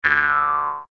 TL_step_on_rake.ogg